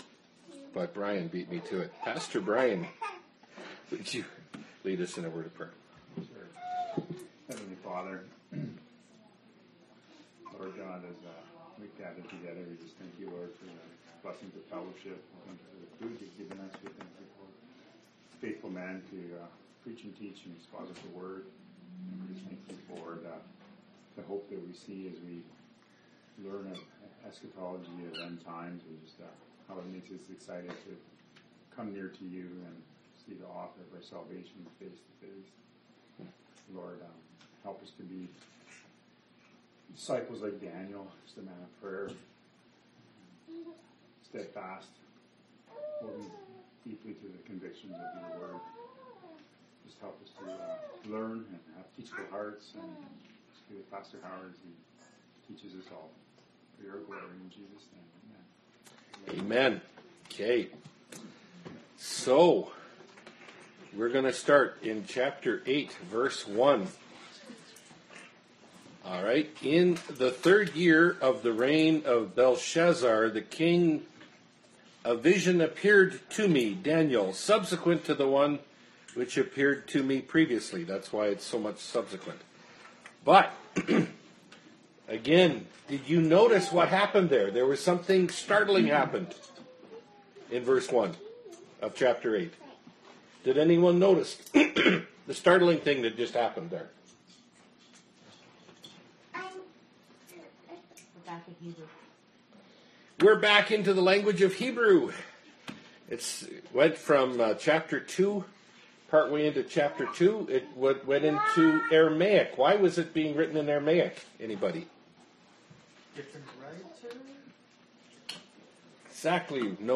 Bible Study – Daniel 8 – Part 2 of 2 (2017)